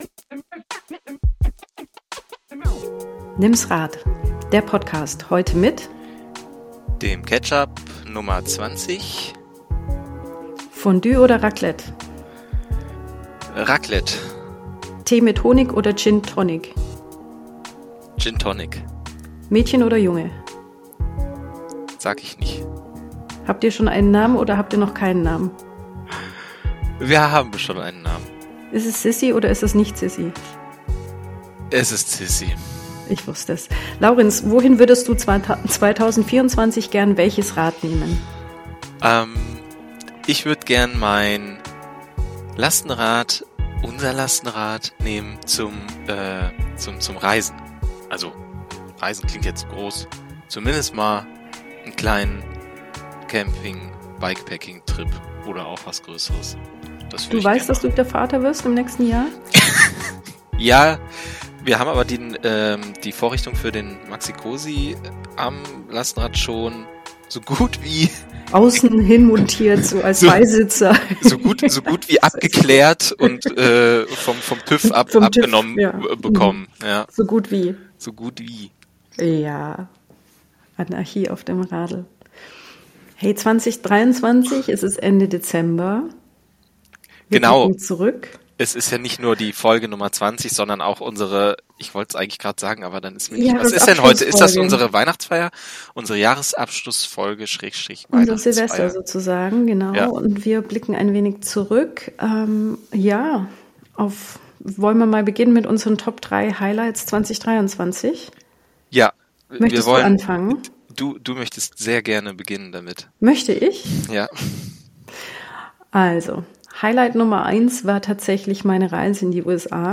Andächtige und sehr persönliche Momente sind aber auch dabei, wie es sich für eine richtig kitschige Weihnachtsausgabe gehört – viel Spaß!